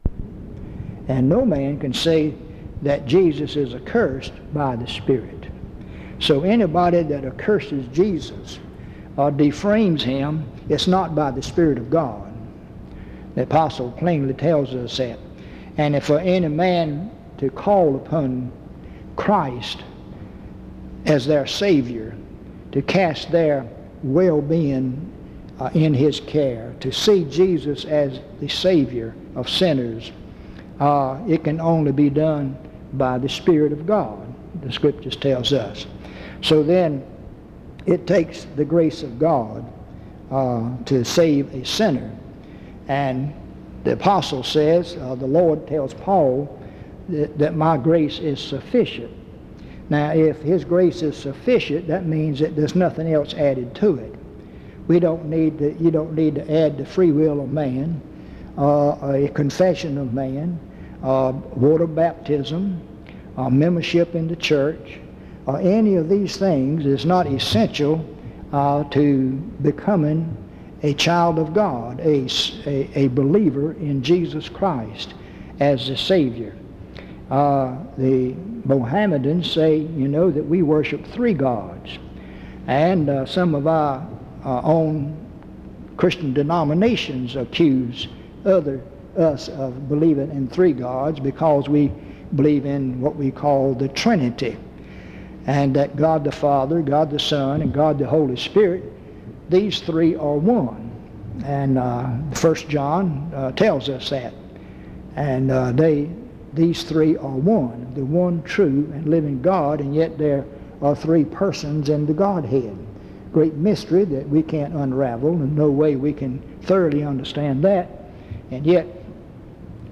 In Collection: Reidsville/Lindsey Street Primitive Baptist Church audio recordings Thumbnail Titolo Data caricata Visibilità Azioni PBHLA-ACC.001_038-A-01.wav 2026-02-12 Scaricare PBHLA-ACC.001_038-B-01.wav 2026-02-12 Scaricare